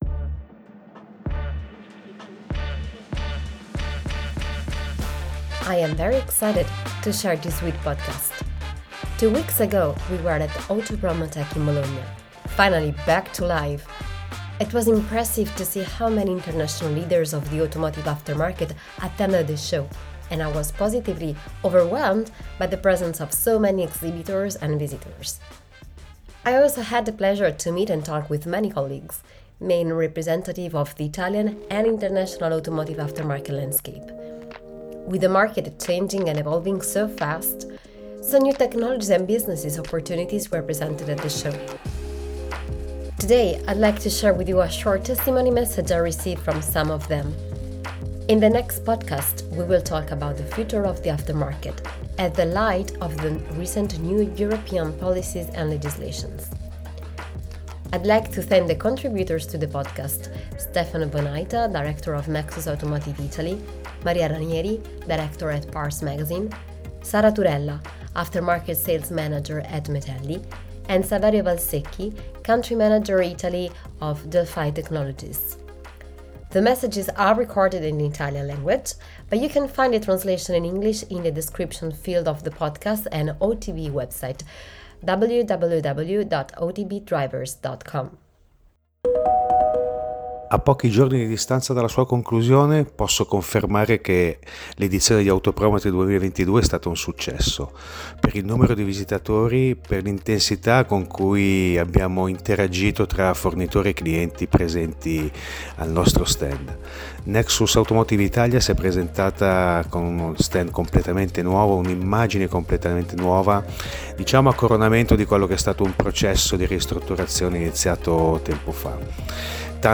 Ep 5 – Interview with Stepsbridge